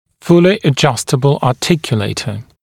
[‘fulɪ ə’ʤʌstəbl ɑːˈtɪkjuleɪtə][‘фули э’джастэбл а:ˈтикйулэйтэ]полностью регулируемый или универсальный артикулятор